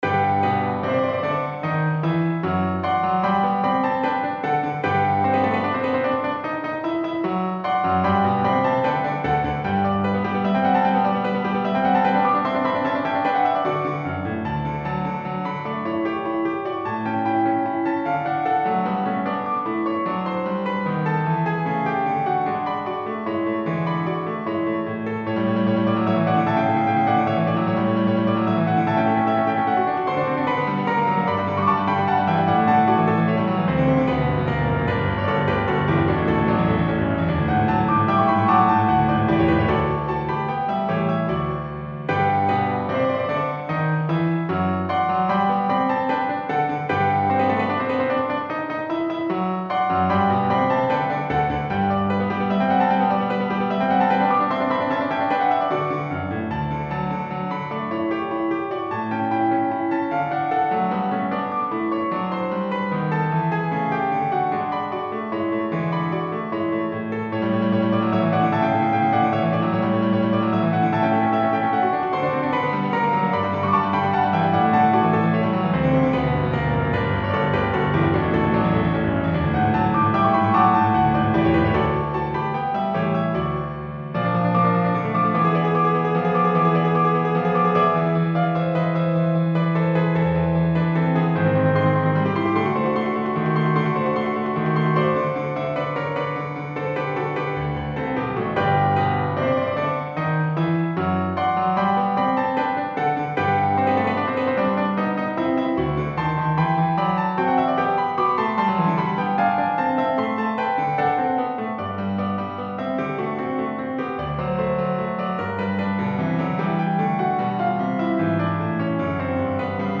Classical Mozart, Wolfgang Amadeus Church Sonata No.9 in G major, K.241 String trio version
Free Sheet music for String trio
church-sonata-9-in-g-major-k-241.mp3